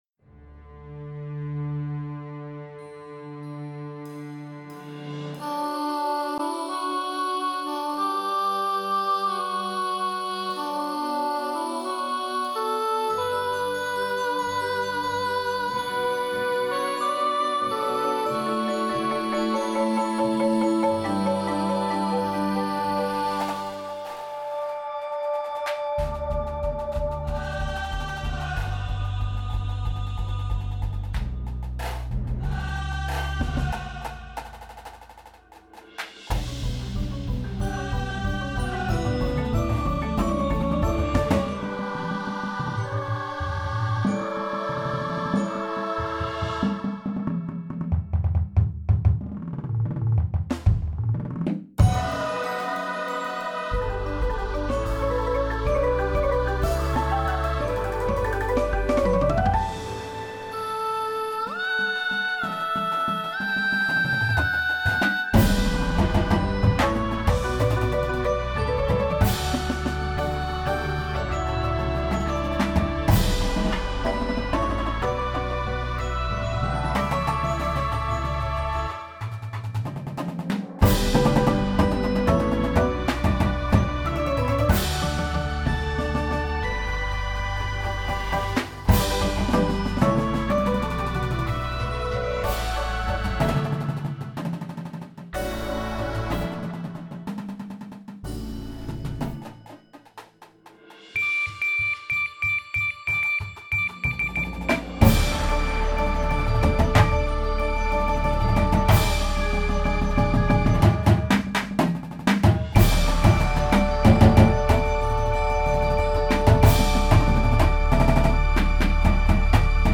Indoor Percussion Shows
Front Ensemble